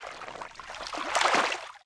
fishing_fail.wav